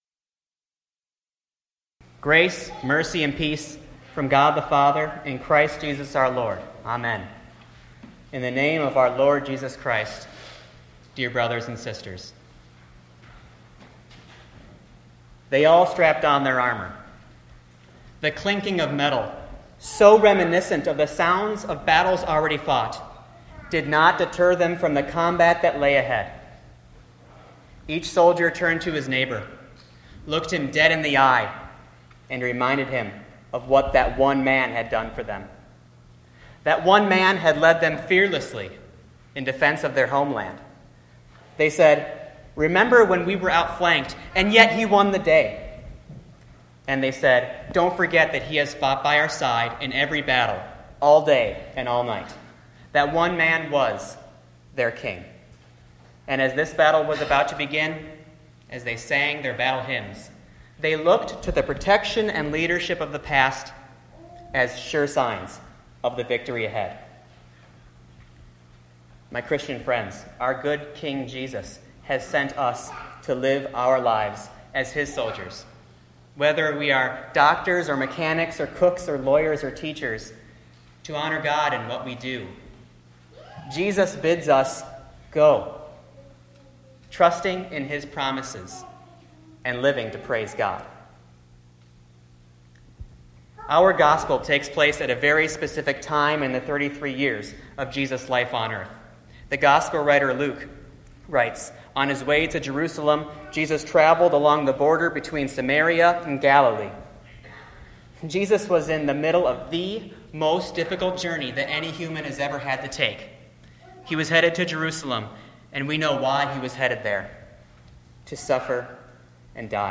The Twenty-First Sunday After Pentecost – Sermon based on Luke 17:11-19